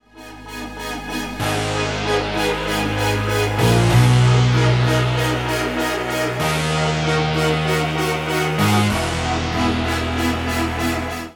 Помогите накрутить бас